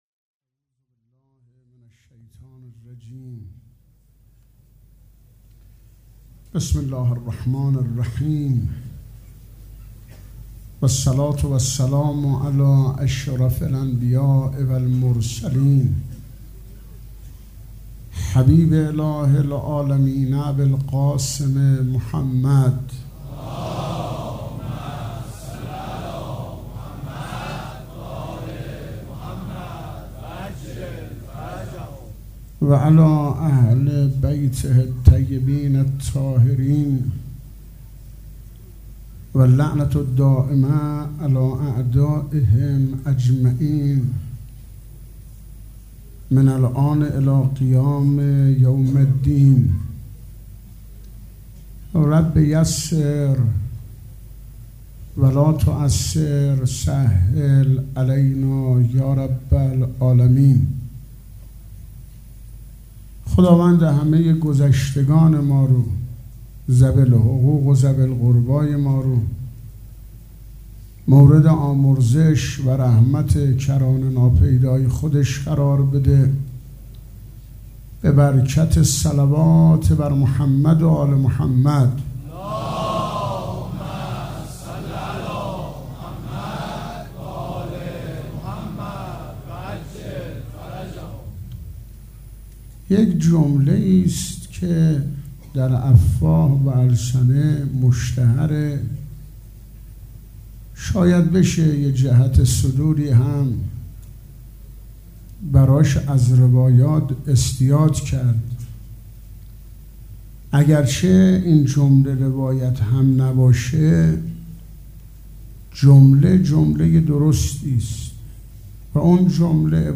سخنرانی
سخنرانی شب اول محرم